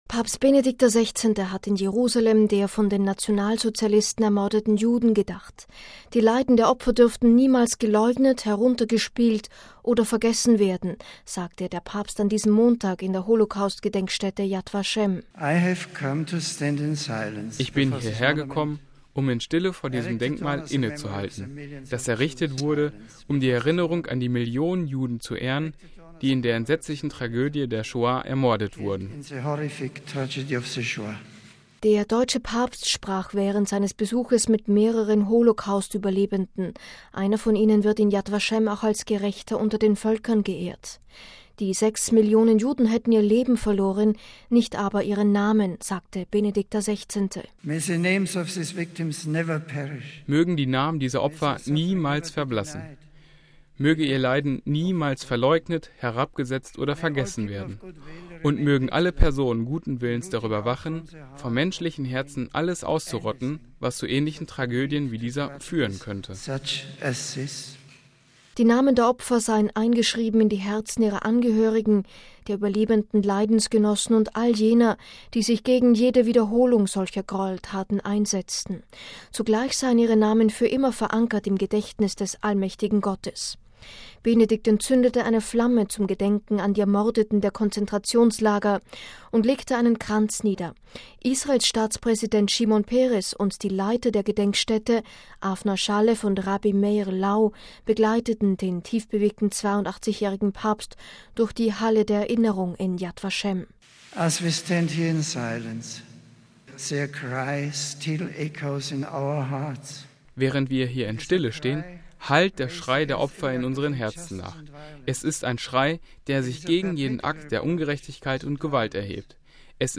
Die Leiden der Opfer dürften niemals geleugnet, heruntergespielt oder vergessen werden, sagte der Papst an diesem Montag in der Holocaust-Gedenkstätte Yad Vashem.